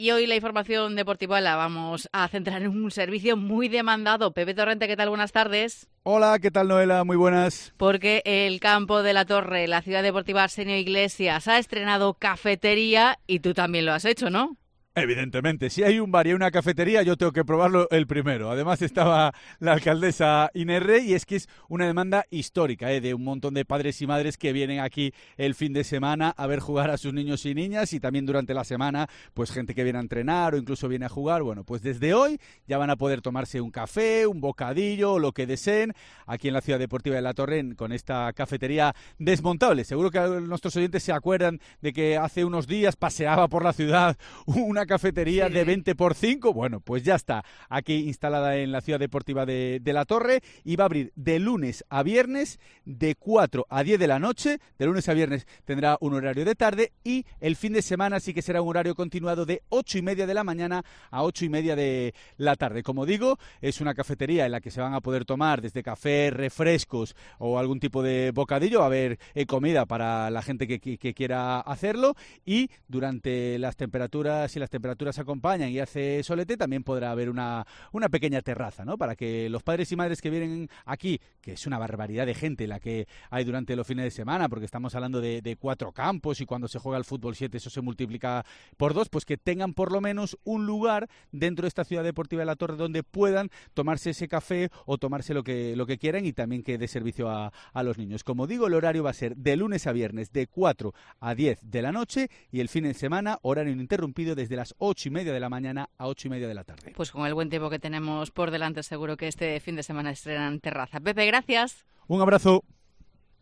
en la apertura de la cafetería de la Ciudad Deportiva Arsenio Iglesias, los Campos de la Torre en A Coruña